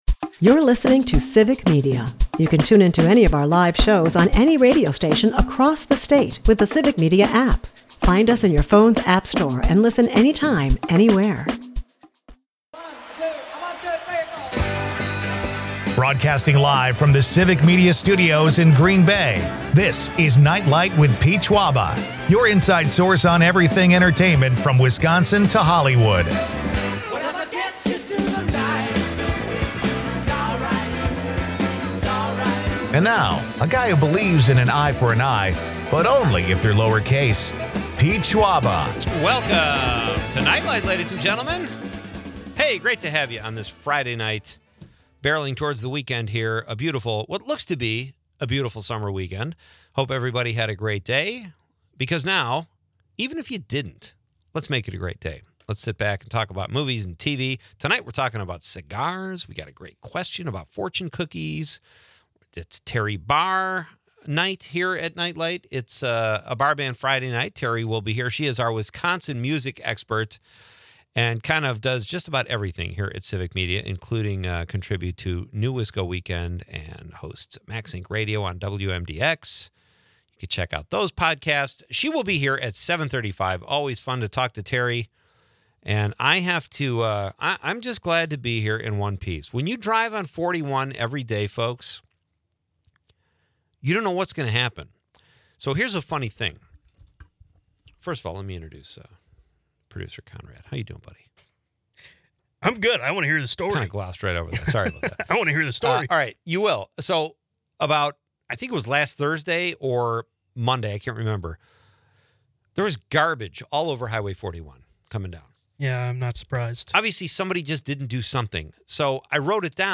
Listeners chime in with their ideal fortune cookie predictions, from world peace to free golf for life.